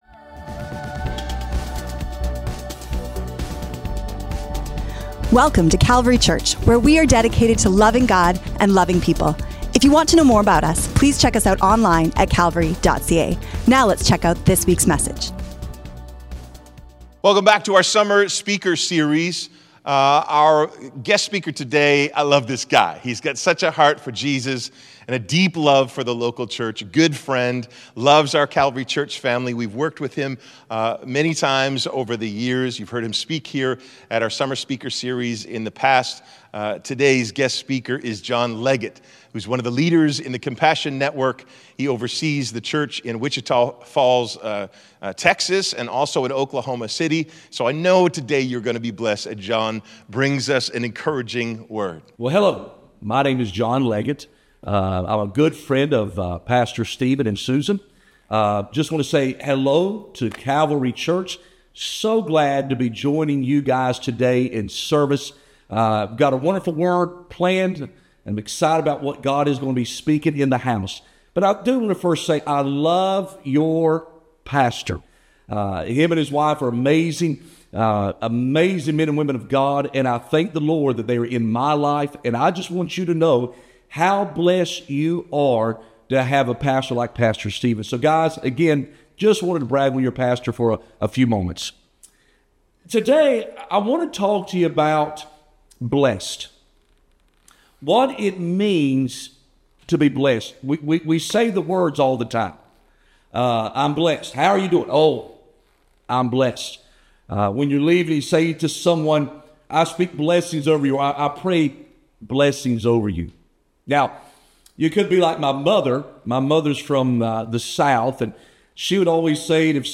Guest Speaker
Current Sermon